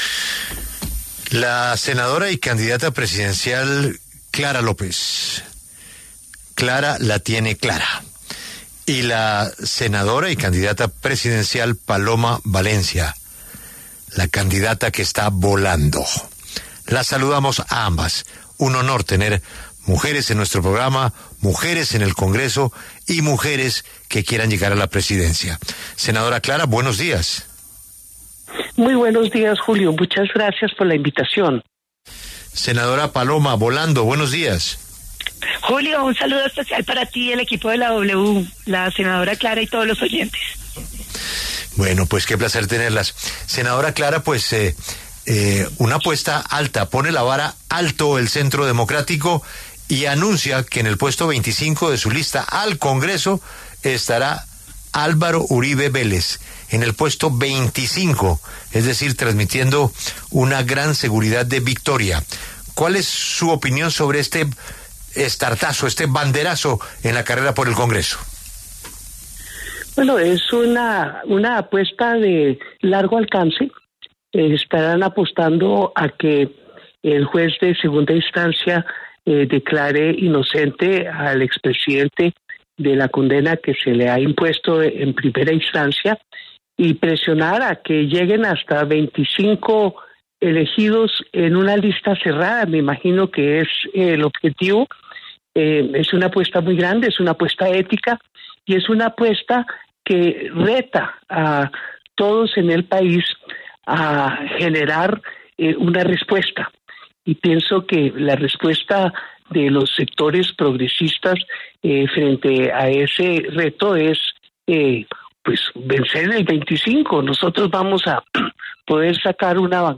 Las senadoras y precandidatas Paloma Valencia, del Centro Democrático, y Clara López, del Pacto Histórico, pasaron por los micrófonos de La W.